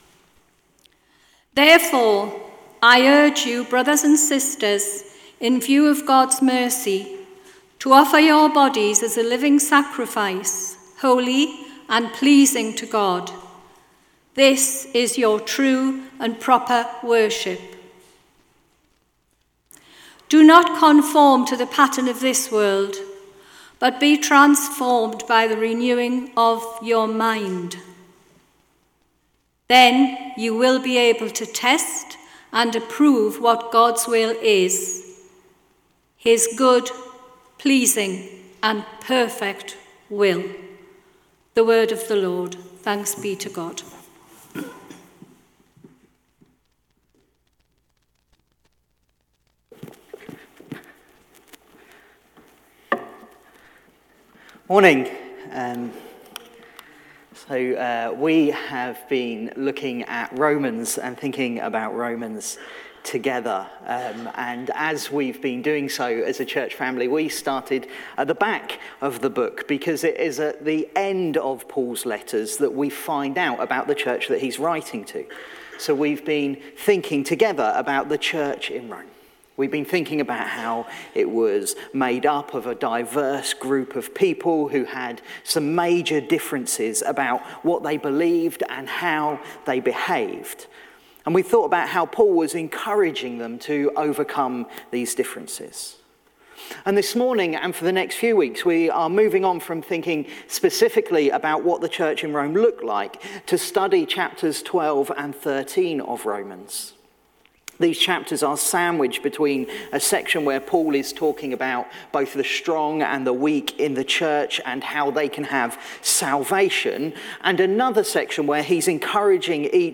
1-2 Tagged with Morning Service Audio